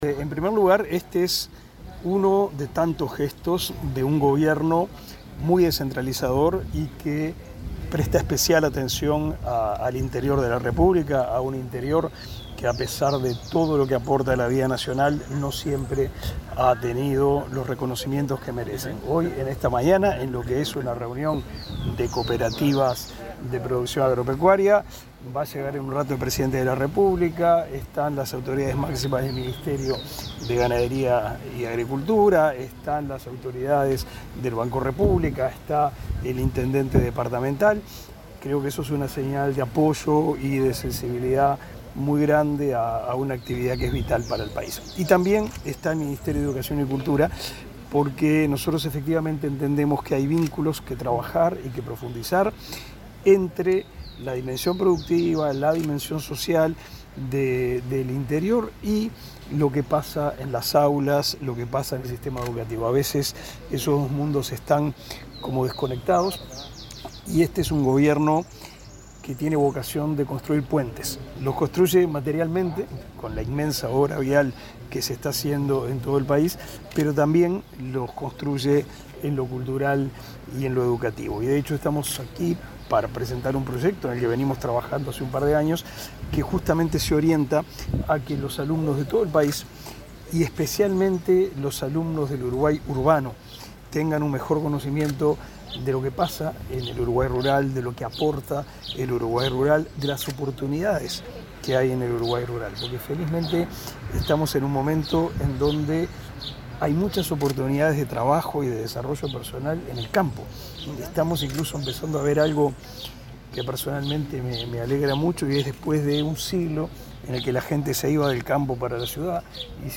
El ministro de Educación y Cultura, Pablo da Silveira, dialogó, este martes 12, con la prensa, en la localidad de Palmar, departamento de Soriano,